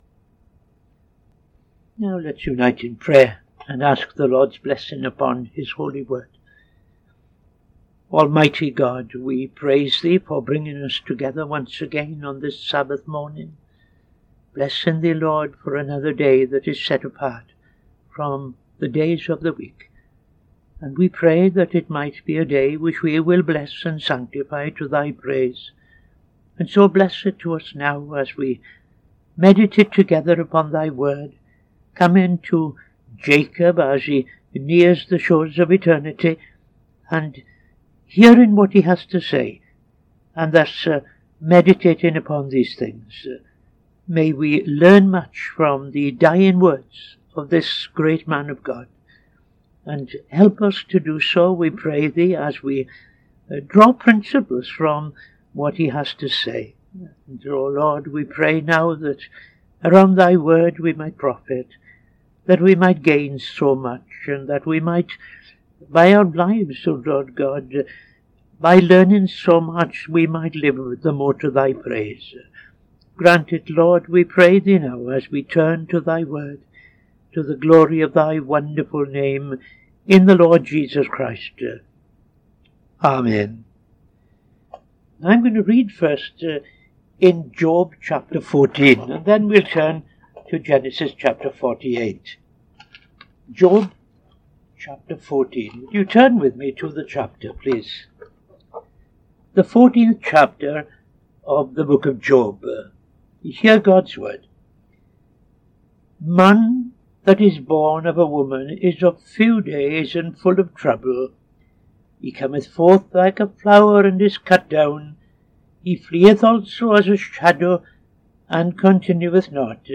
Midday Sermon - TFCChurch